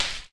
default_dig_crumbly.1.ogg